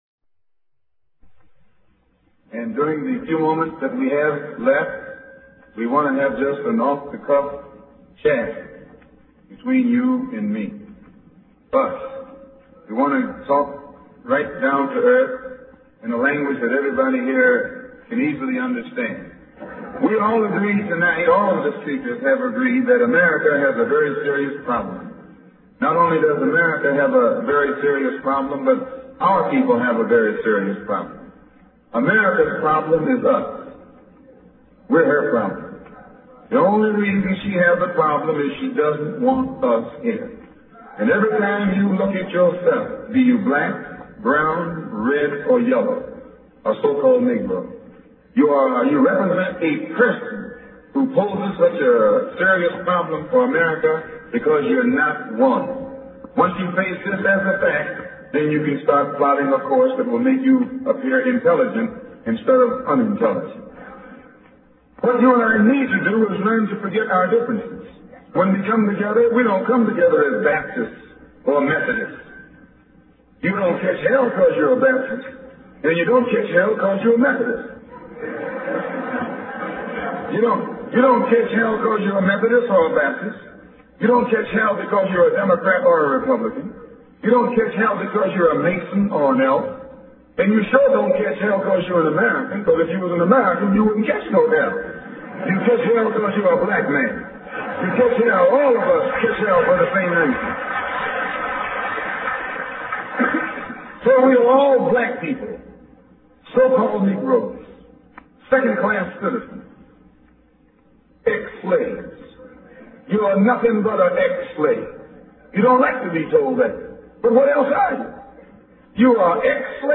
delivered on 10 Nov, 1963 in Detroit, MI